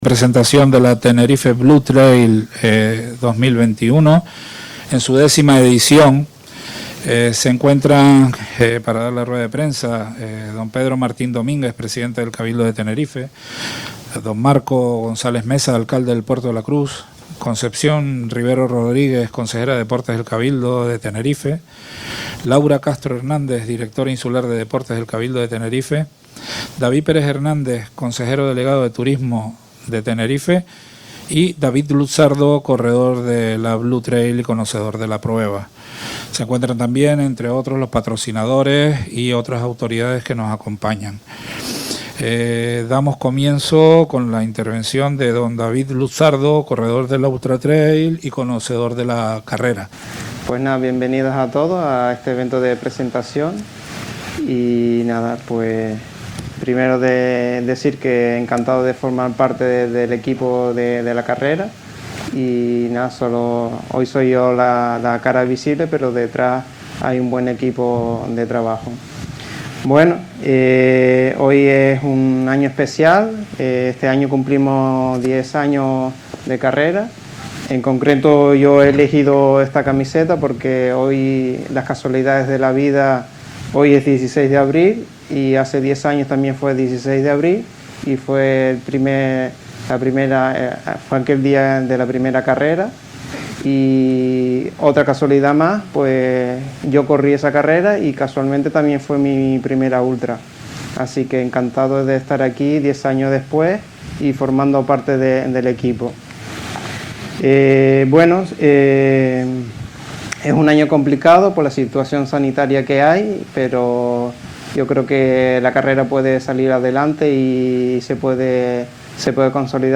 Acto realizado en el Lago Martiánez